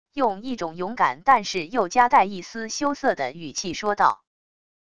用一种勇敢但是又夹带一丝羞涩的语气说道wav音频